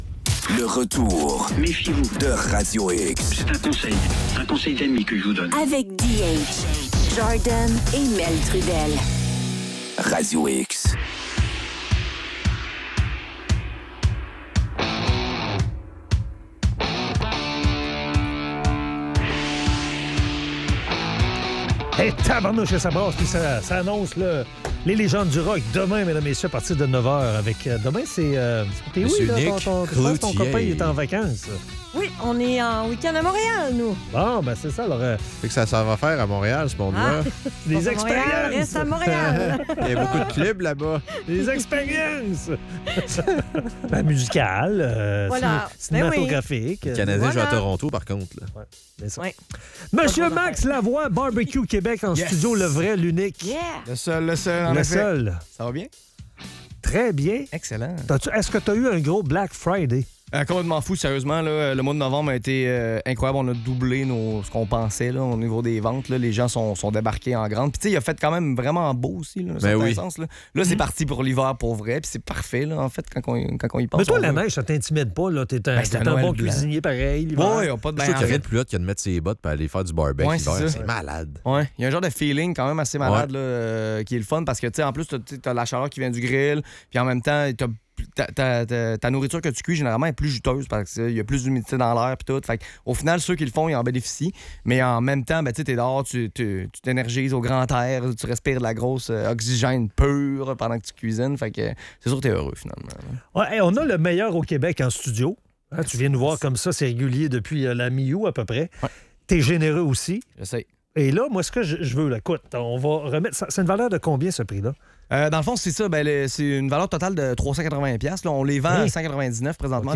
BBQ Québec en studio